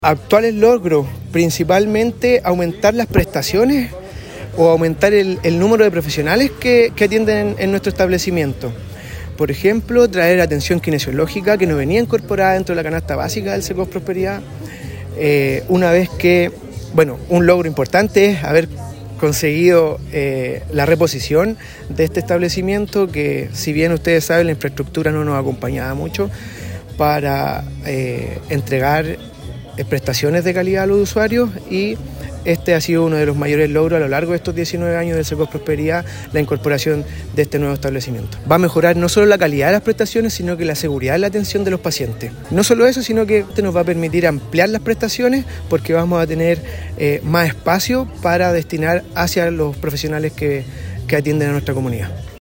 Con una emotiva ceremonia que reunió a autoridades, profesionales de la salud y vecinos del sector poniente de Curicó, el Centro Comunitario de Salud Familiar (Cecosf) Prosperidad conmemoró un nuevo aniversario, reafirmando su rol como referente en la atención primaria.